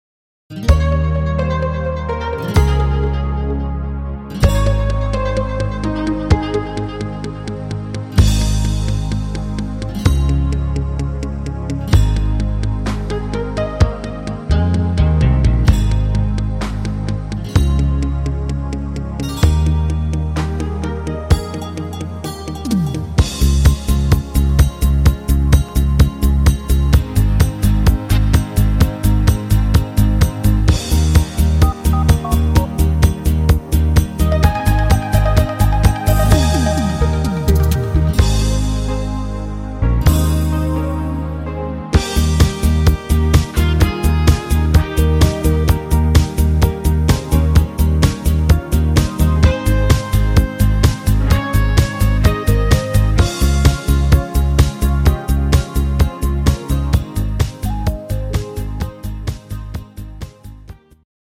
Rhythmus  Discofox
Art  Deutsch, Schlager 2020er